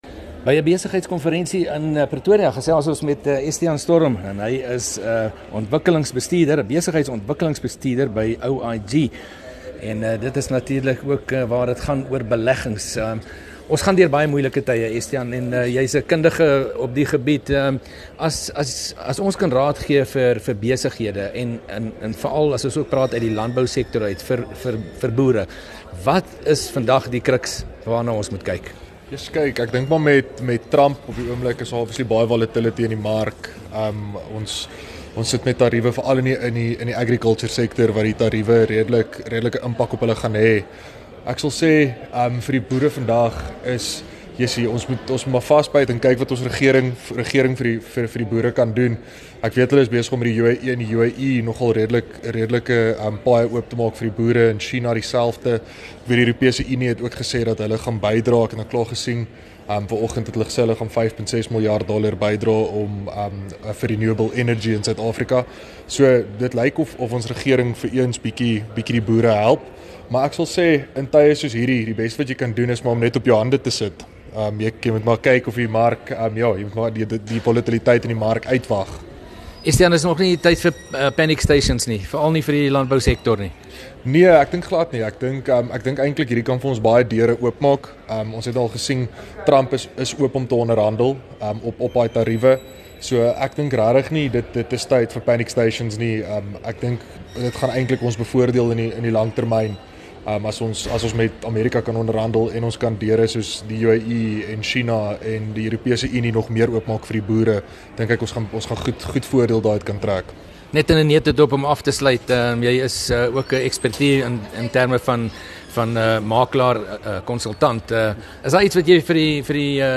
by 'n besigheidskonferensie gesels met 'n ekonomiese kenner